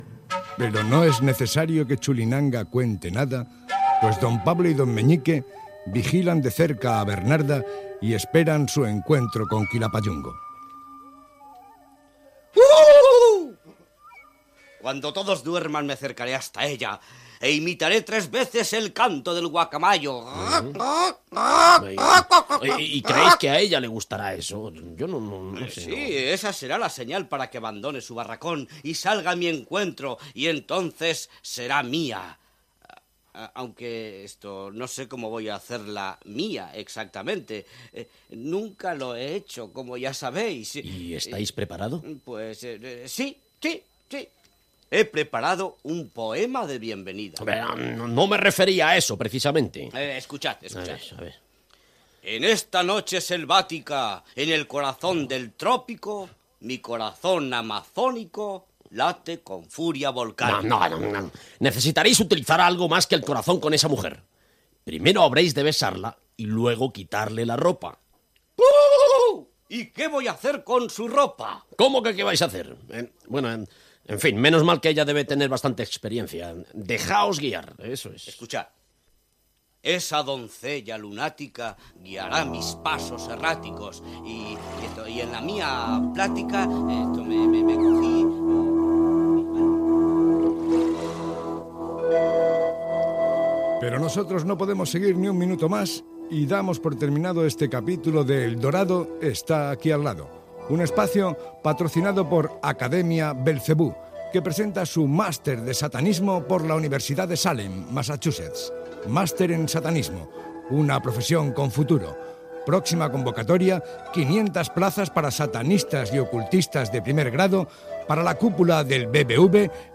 Fragment de l'úlltim capítol (128) del serial "El dorado está aquí al lado", crèdits i publicitat fictícia.
Entreteniment